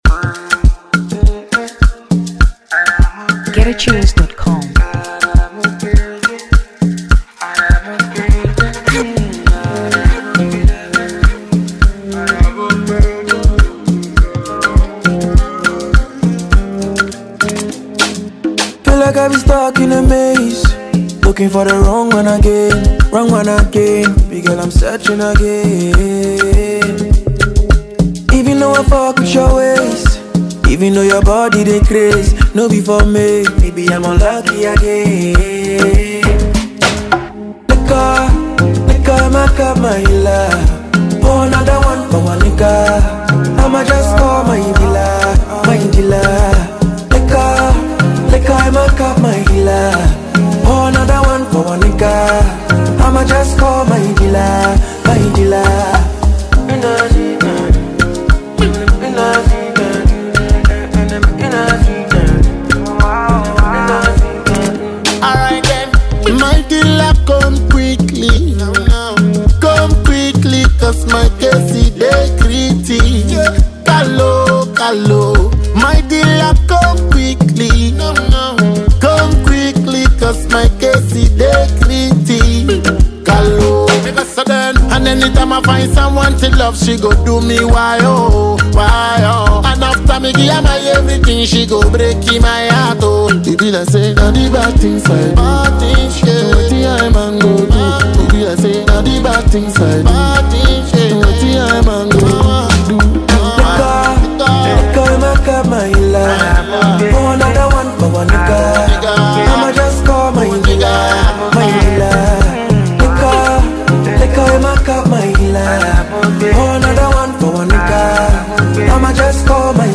Afrobeats 2023 Ghana